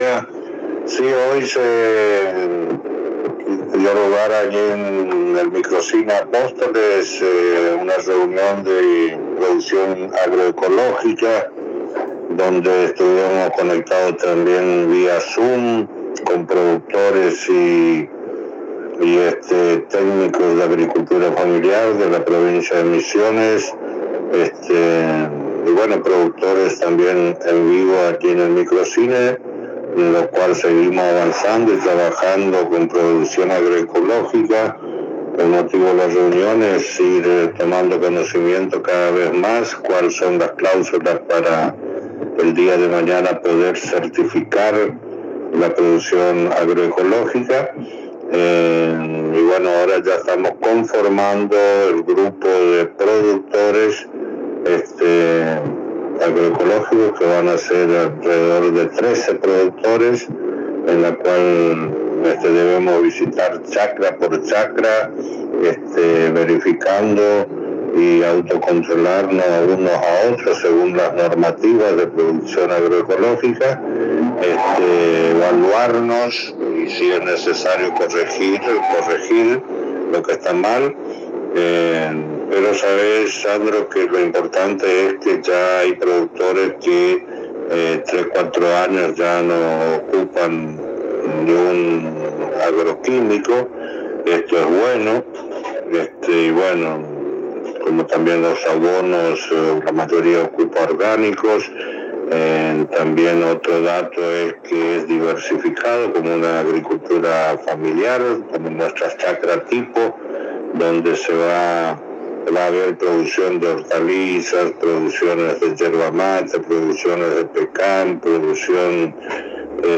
De acuerdo a la charla en exclusiva con la ANG y el Secretario de la Producción Juan Ramón Poterala del Municipio de Apóstoles se realizó una reunión en el Microcine Hugo del Carril con productores que conformaron el grupo de productores agroecológicos donde un número de ellos hace ya cuatro años que llevan adelante producción Agroecológica.